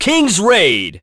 Roman-Vox_Kingsraid.wav